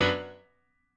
admin-leaf-alice-in-misanthrope/piano34_1_011.ogg at a8990f1ad740036f9d250f3aceaad8c816b20b54